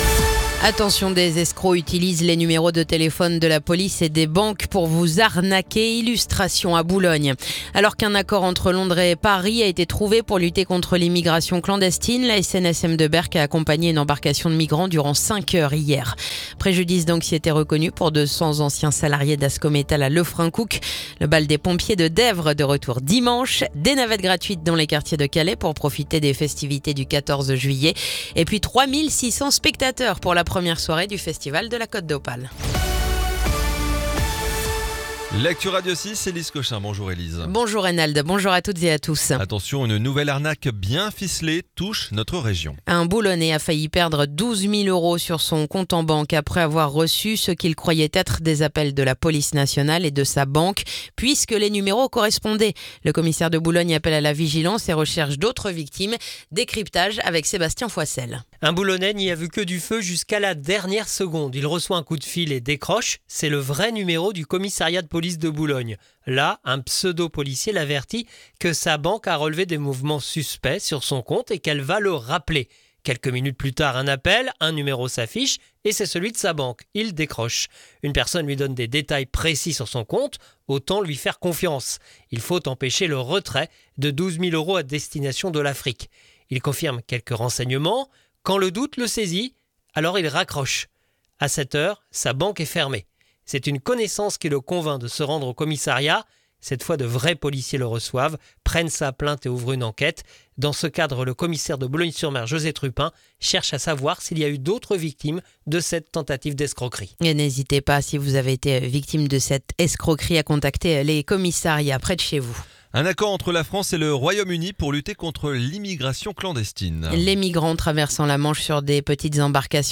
Le journal du vendredi 11 juillet